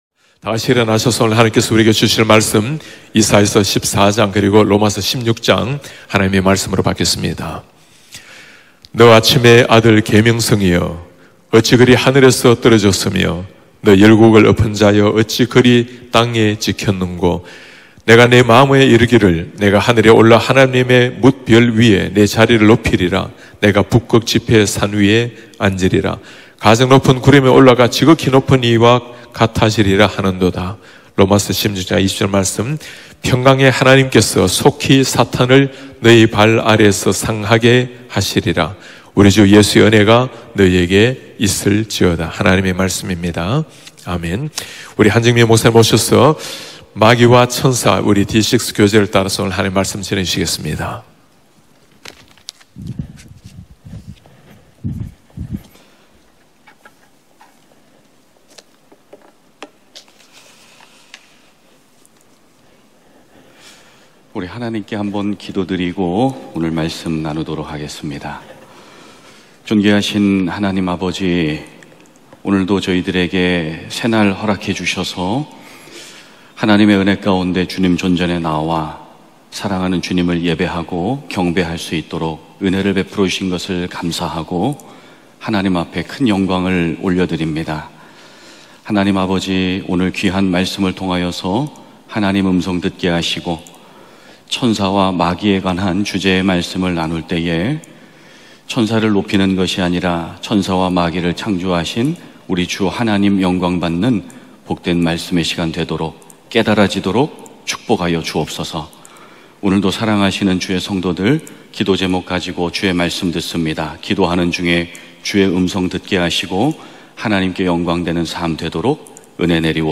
예배: 토요 새벽